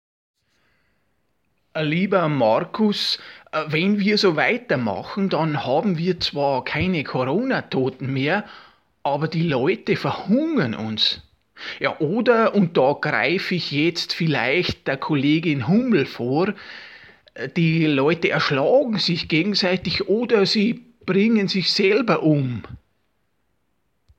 Es gibt aber auch einen zweiten Vorteil, den sein Rücktritt böte: Er müsste sich nicht ständig der Ratschläge seines Stellvertreters Hubert Aiwanger erwehren, der Söder kürzlich via Bayerischem Rundfunk vertrauensvoll Folgendes mitteilte: